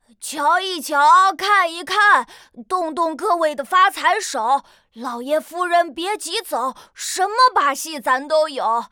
序章与第一章配音资产